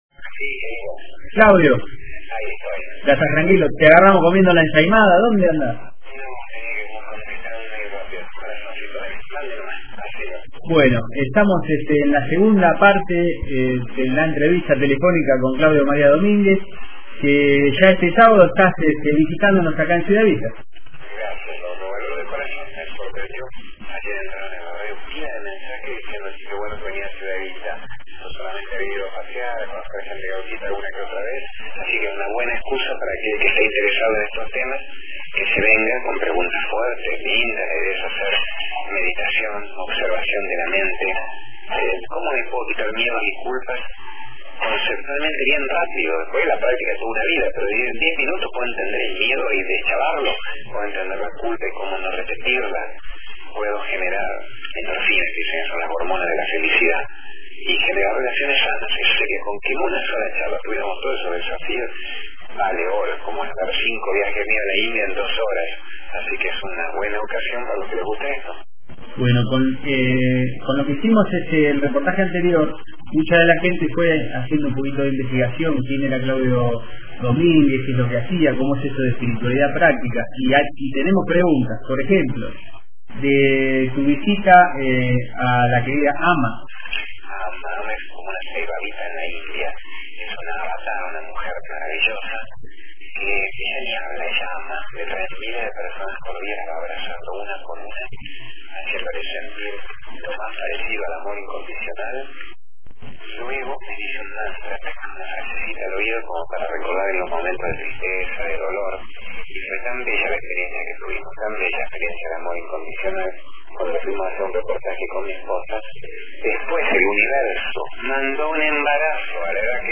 REPORTAJE ESPECIAL